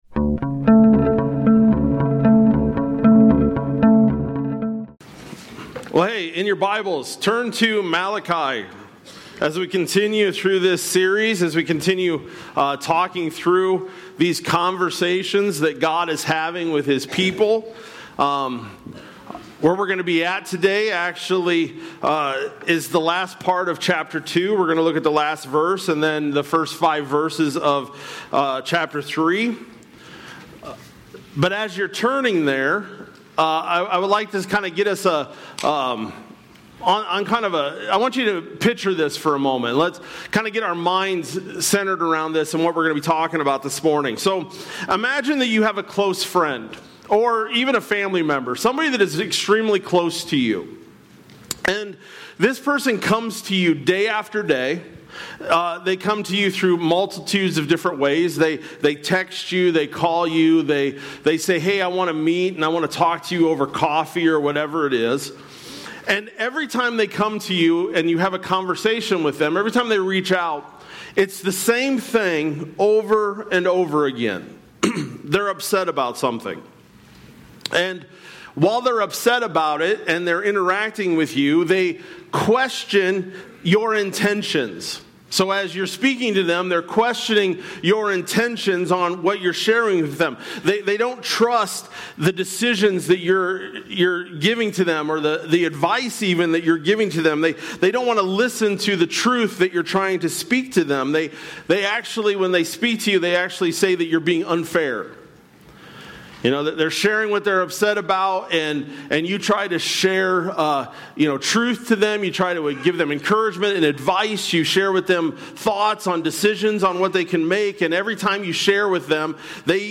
July-13-Sermon-Audio.mp3